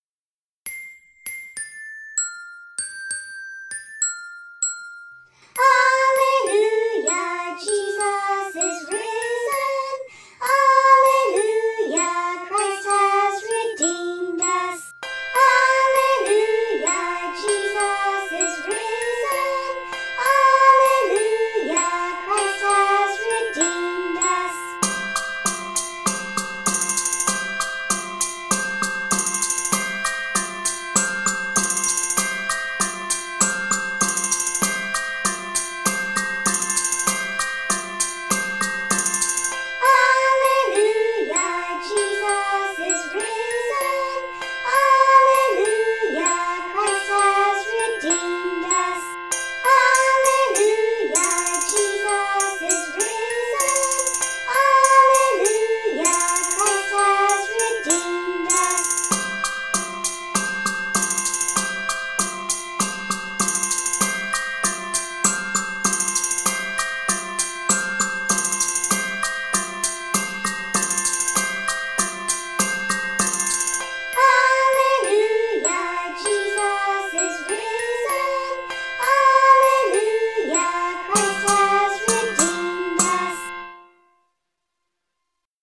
Ensemble
Unison/Congregation Unison choir and instruments.
Anthem Anthem; choir and percussion parts easy to memorize.